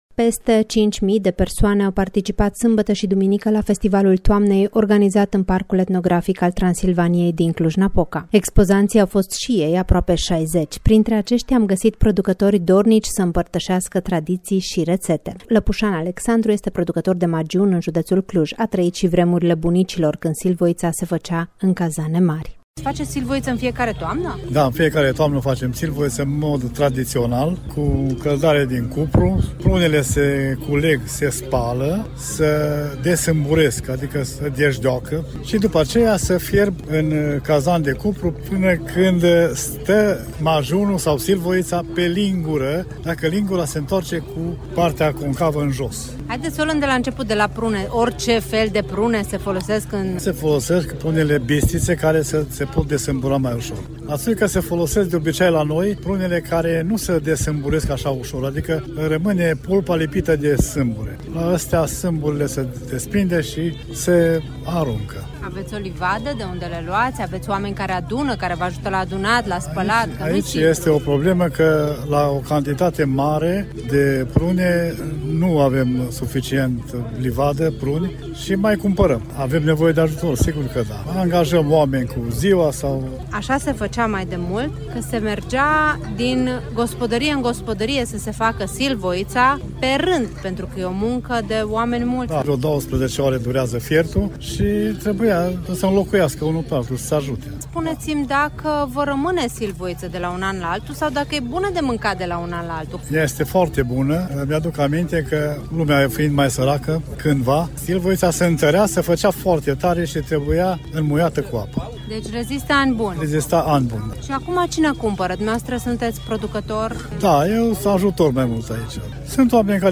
Peste 5.000 de persoane au participat sâmbătă și duminică la Festivalul Toamnei organizat în Parcul Etnografic al Transilvaniei din Cluj-Napoca.
Printre aceștia am găsit producători dornici să împărtășească tradiții și rețete.
festivalul-toamnei-traditii.mp3